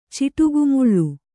♪ ciṭugumuḷḷu